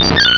pokeemerald / sound / direct_sound_samples / cries / sunkern.aif